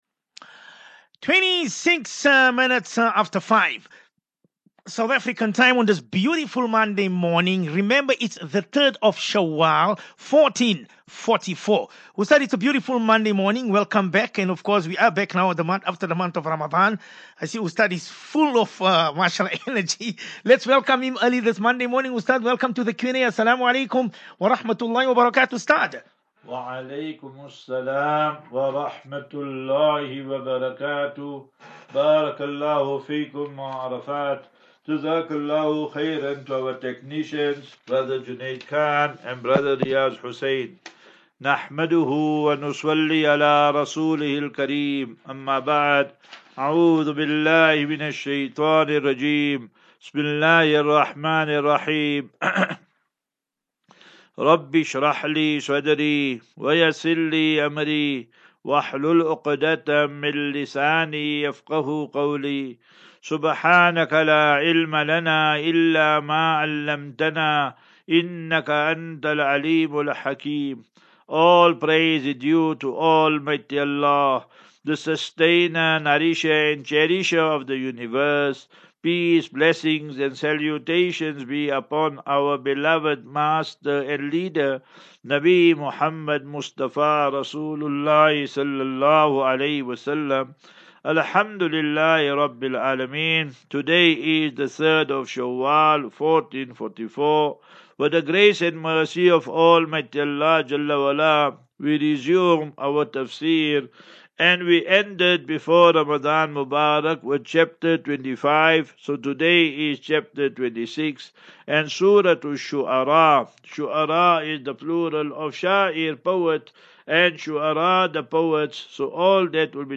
As Safinatu Ilal Jannah Naseeha and Q and A 24 Apr 24 Apr 23 Assafinatu